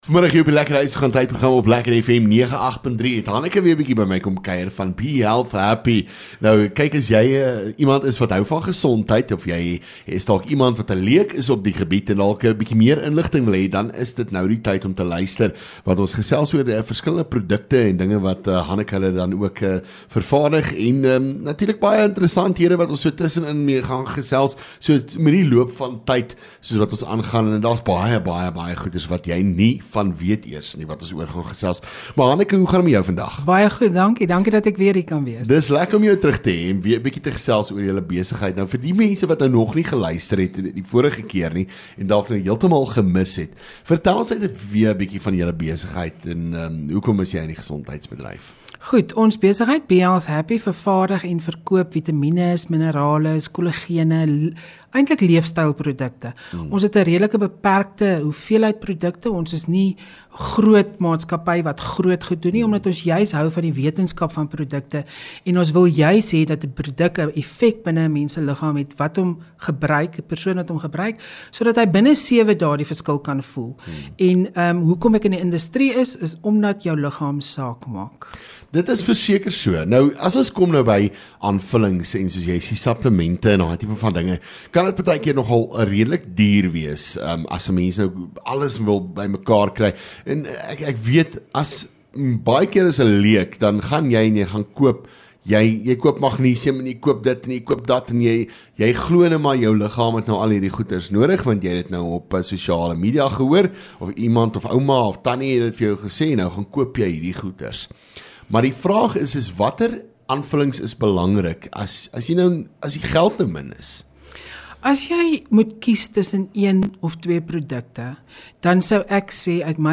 LEKKER FM | Onderhoude 30 Jan Be Health Happy